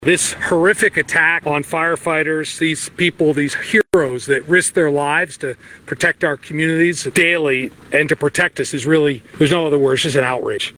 Montana Governor Greg Gianforte, during a forest management press briefing, urged prayers for the victims and condemned the attack as a targeted act of violence against first responders.